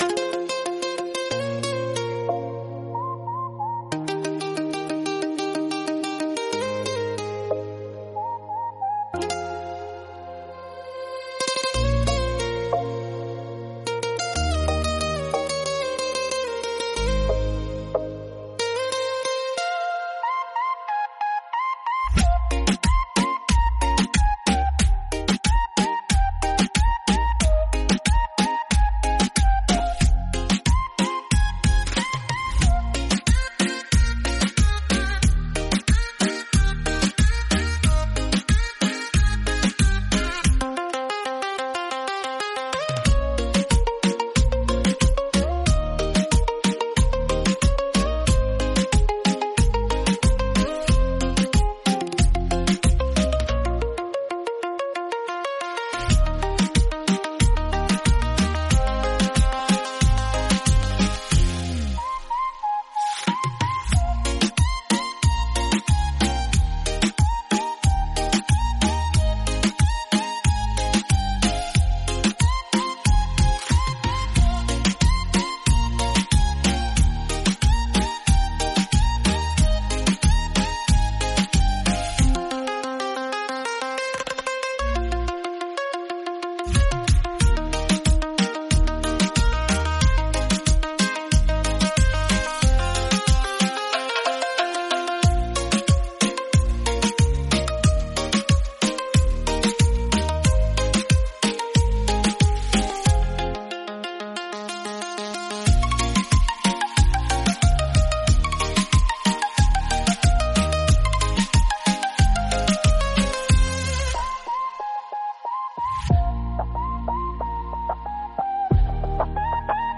Beat Reggaeton Instrumental